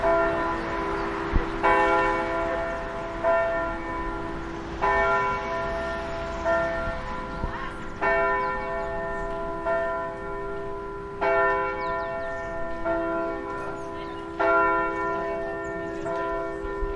Churchbell 1
描述：在街道上的一个教堂。用Zoom H1 44 kHz 16位立体声WAV录制
Tag: 教堂 现场录制 城市